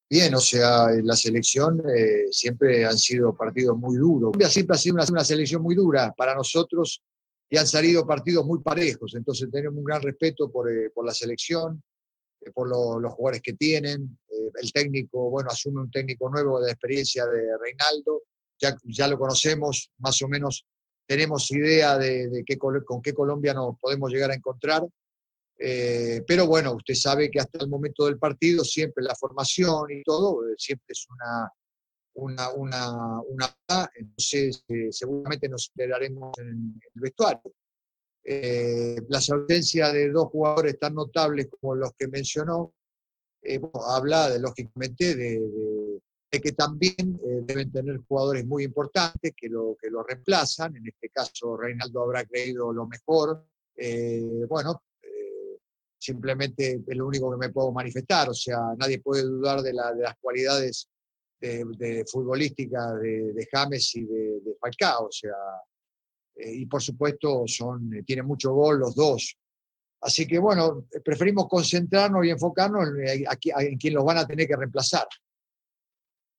Ricardo Gareca - DT Selección Perú, Rueda de prensa previa a Colombia